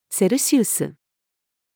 セルシウス-female.mp3